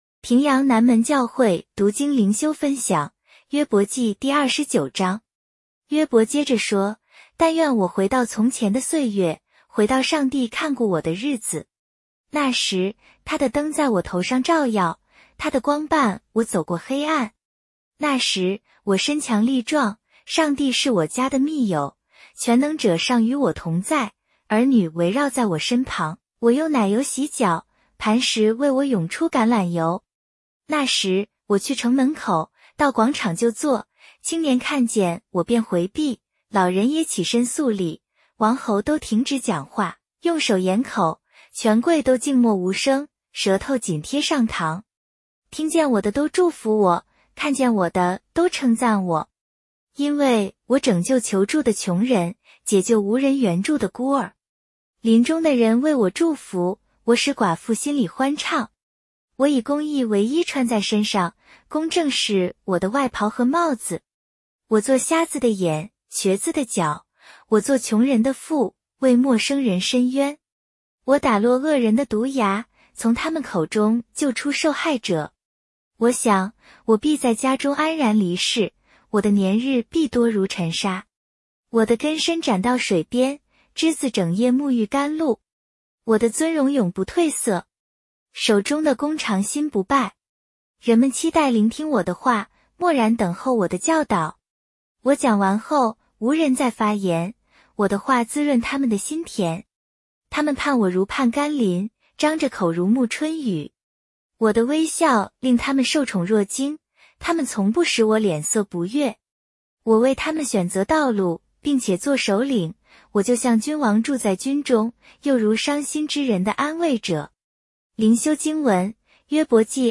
普通话朗读——伯29